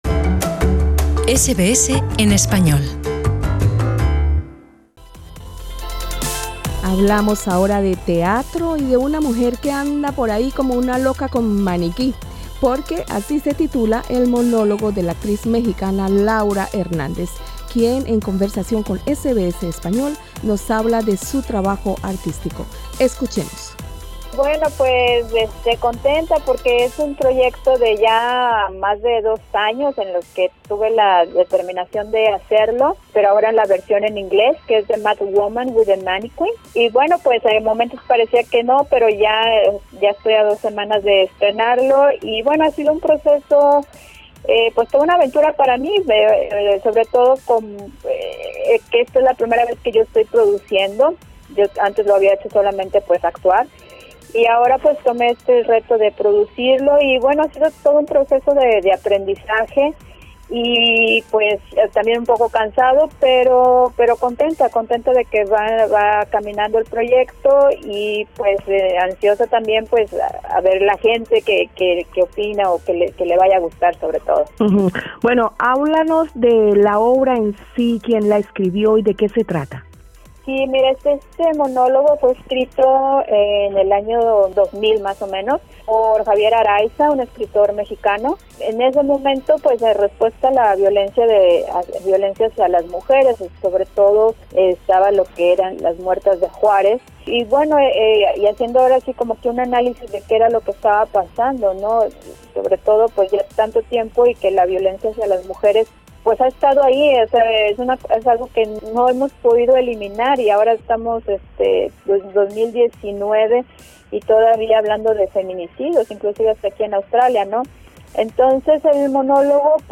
Escucha en nuestro podcast la entrevista